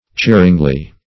cheeringly - definition of cheeringly - synonyms, pronunciation, spelling from Free Dictionary Search Result for " cheeringly" : The Collaborative International Dictionary of English v.0.48: Cheeringly \Cheer"ing*ly\, adv.
cheeringly.mp3